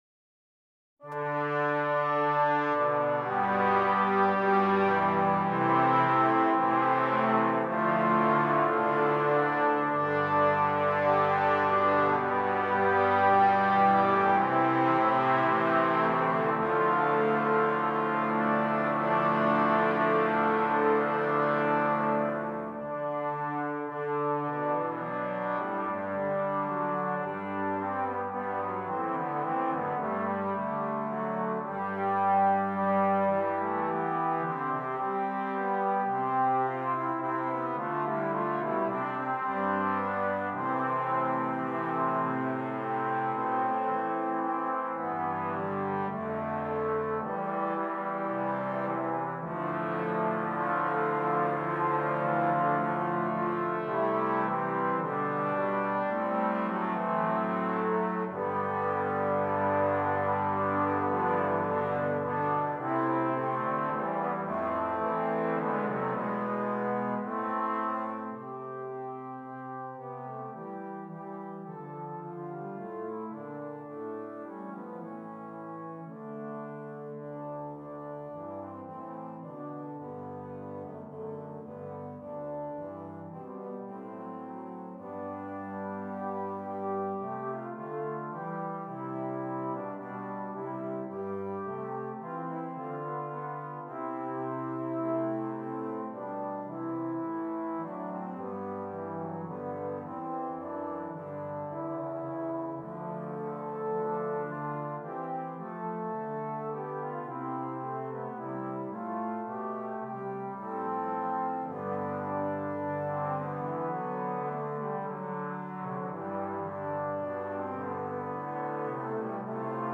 6 Trombones